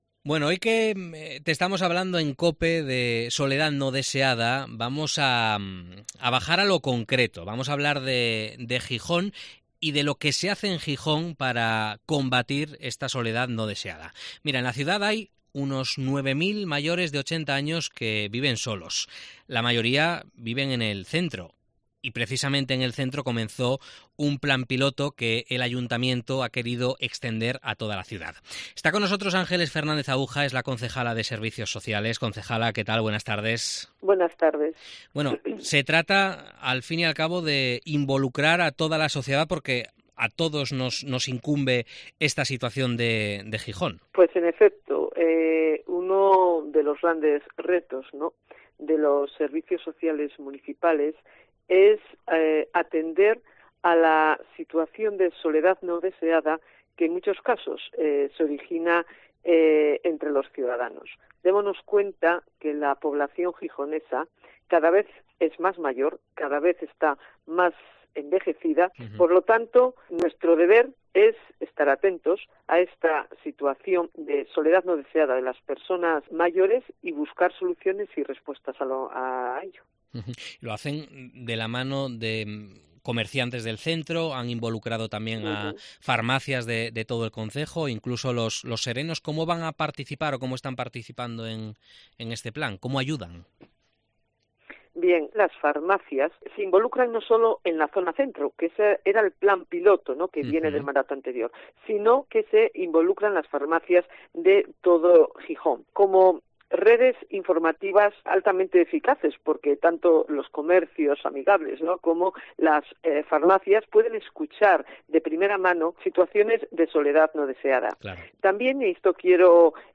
Entrevista a la concejala de Servicios Sociales de Gijón, Ángeles Fernández-Ahuja